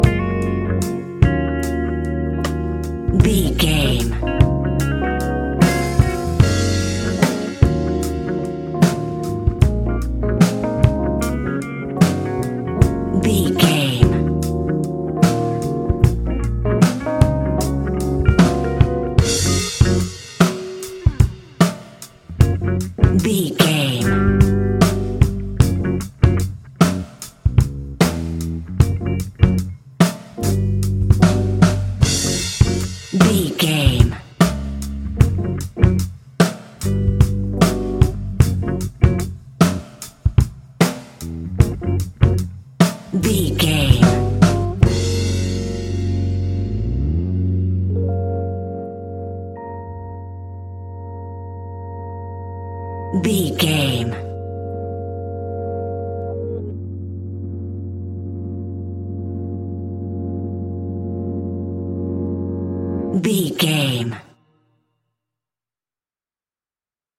Epic / Action
Fast paced
In-crescendo
Uplifting
Ionian/Major
F♯
hip hop
instrumentals